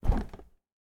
fridge_open.ogg